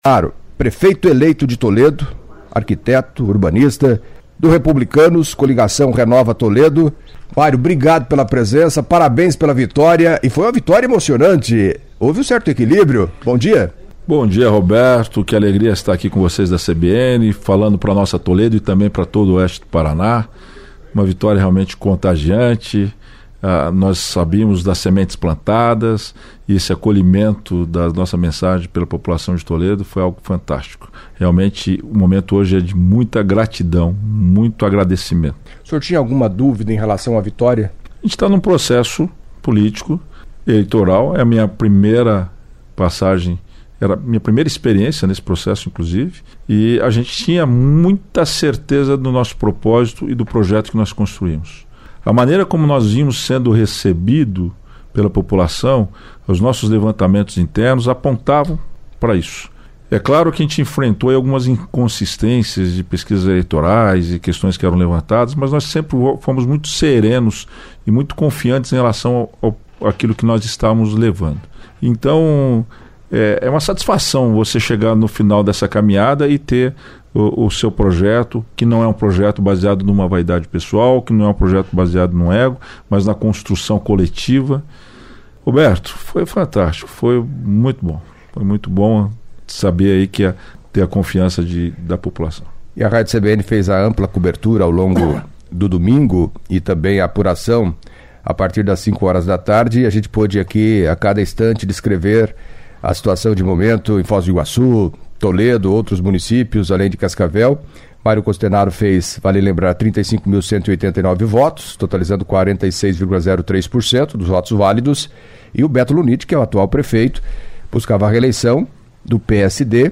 Em entrevista à CBN Cascavel nesta quarta-feira (09) Mario Costenaro, prefeito eleito de Toledo, falou da vitória e como pretende governar o município pelos próximos quatro anos, acompanhe